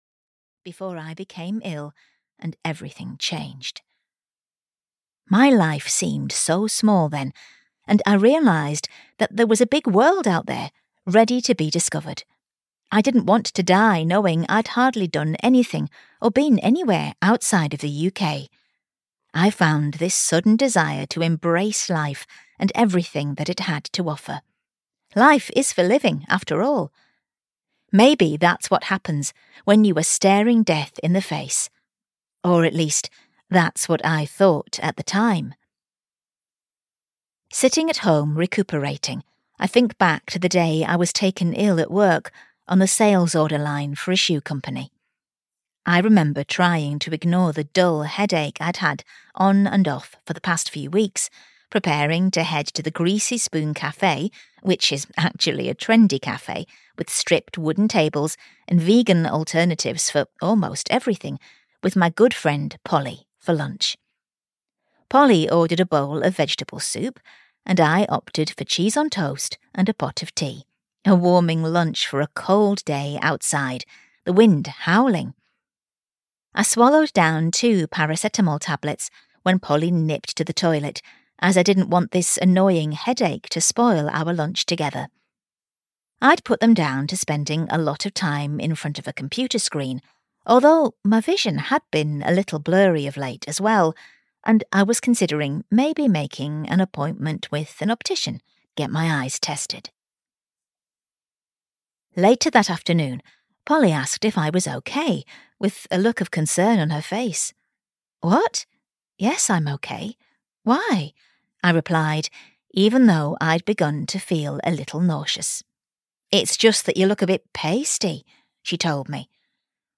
Take a Chance on Greece (EN) audiokniha
Ukázka z knihy